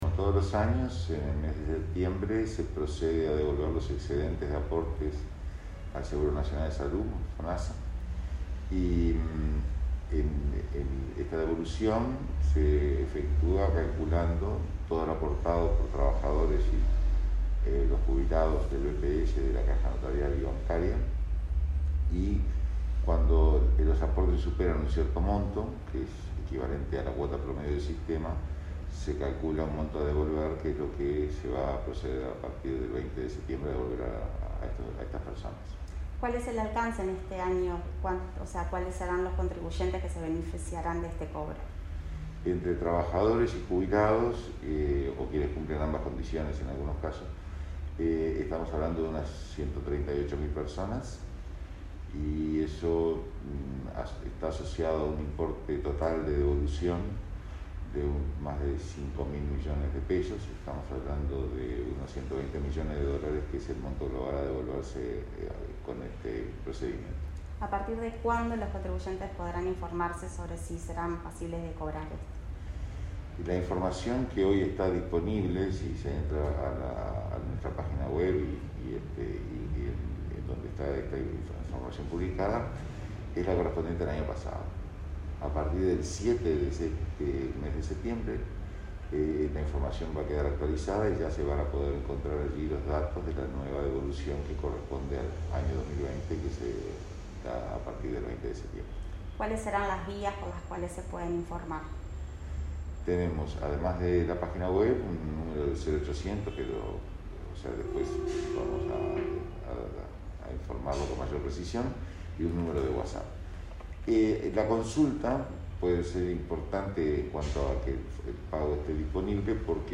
Entrevista al presidente del BPS, Hugo Odizzio